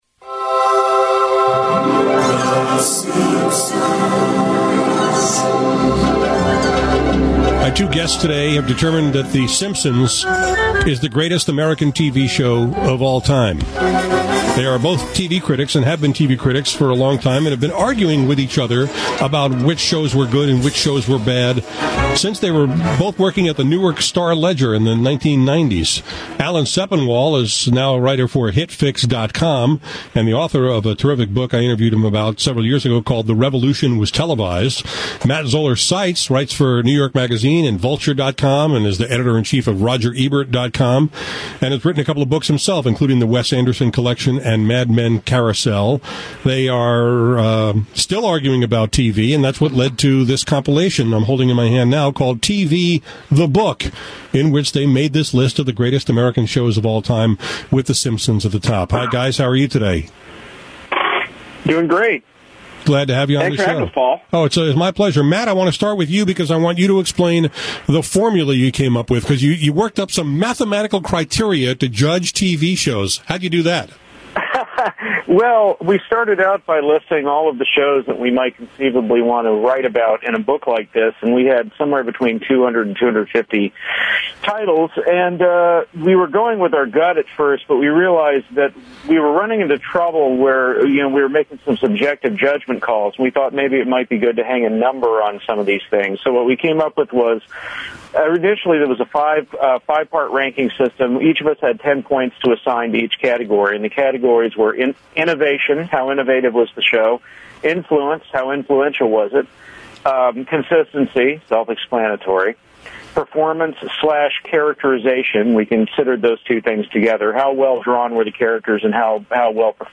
All these years later, Matt and Alan are still arguing about TV, which led to “TV: The Book,” in which they rank the greatest American shows of all time. When they both joined me on the air, I asked them: